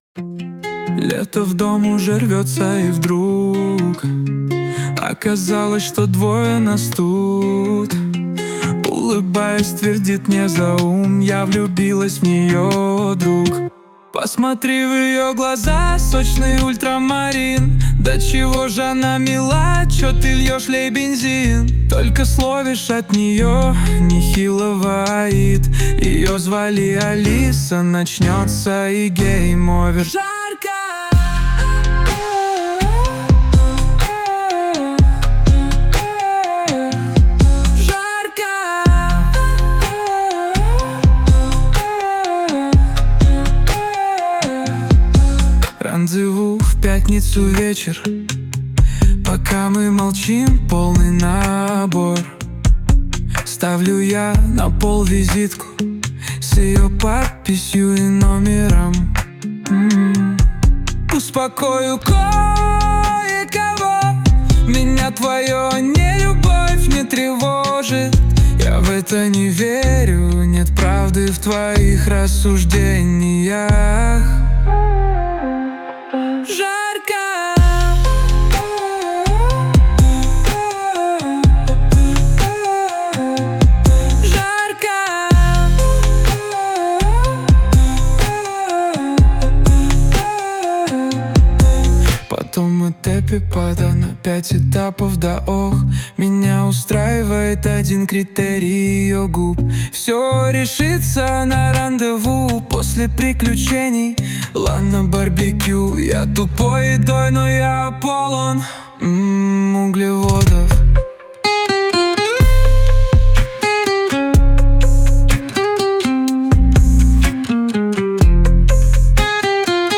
RUS, Romantic, Lyric, Rap | 17.03.2025 16:29